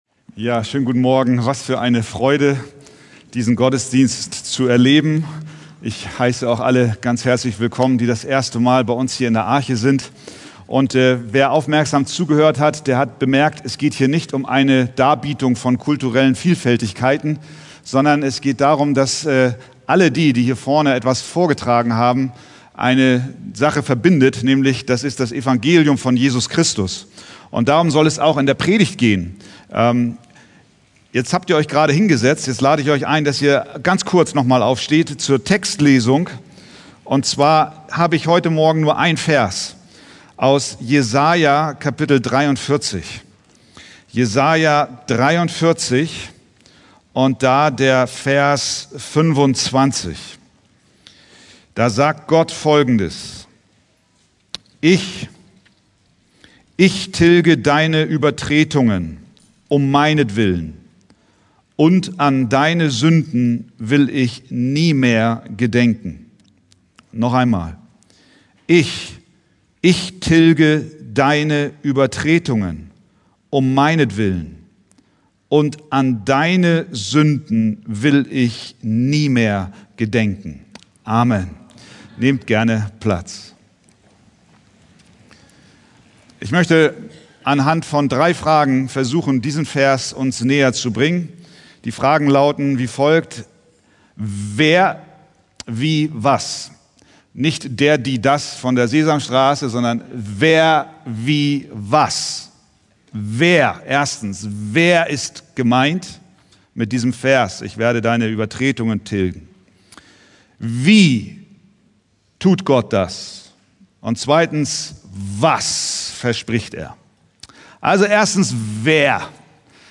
Internationaler Gottesdienst 2024 Predigttext: Jesaja 43,25